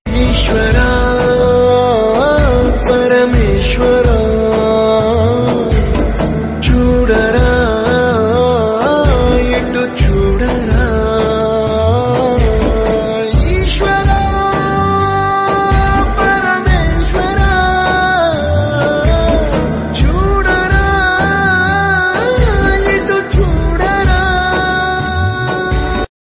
Category: Devotional Ringtones